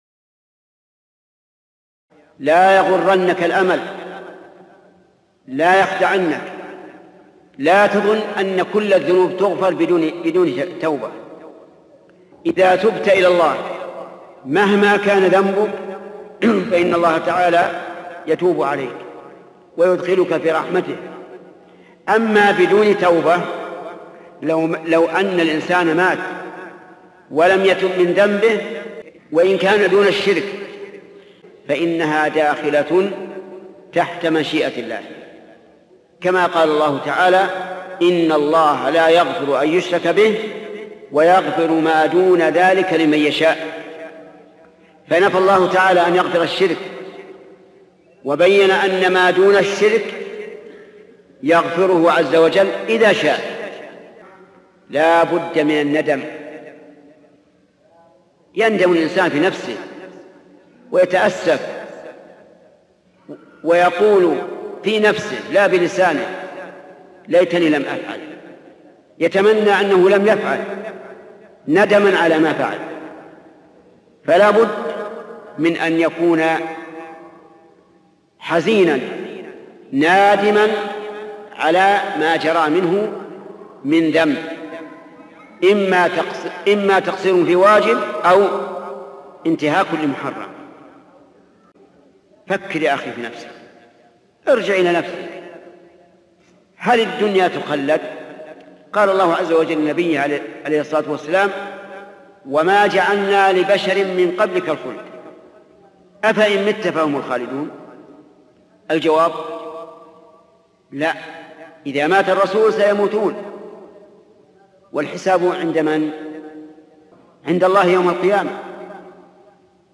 شبكة المعرفة الإسلامية | الدروس | لا تقنطوا من رحمة الله - رسالة لكل مسلم - بدون مؤثرات |محمد بن صالح العثيمين